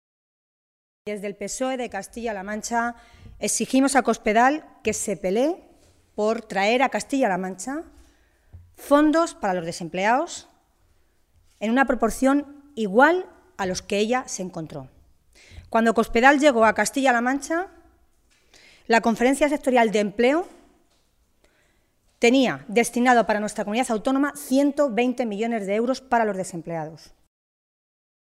Tolón se pronunciaba de esta manera en una comparecencia ante los medios de comunicación esta mañana, en Toledo, en la que pedía que el incremento de los fondos para combatir el paro para este año “fuera, al menos, proporcional al incremento del desempleo en nuestra región, que es del 15 por ciento el último año y del 22 por ciento desde que gobierna Cospedal”.
Cortes de audio de la rueda de prensa